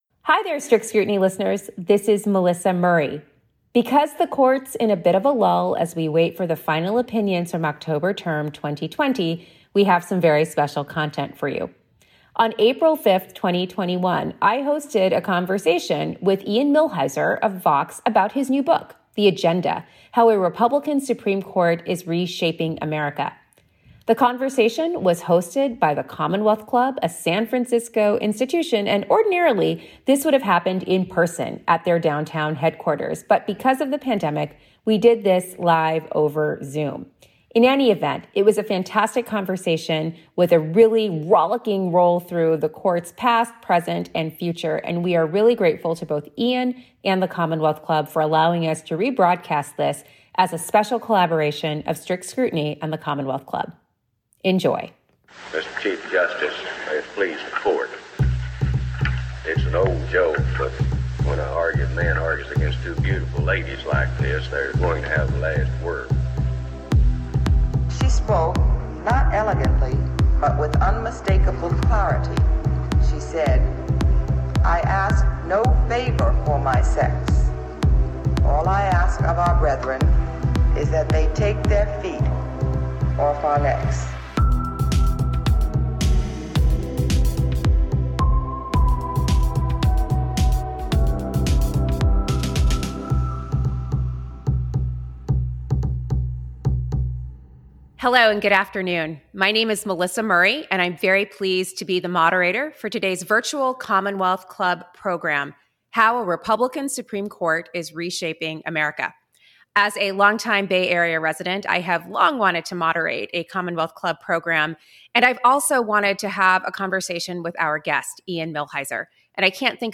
This conversation was originally an event with the Commonwealth Club in April.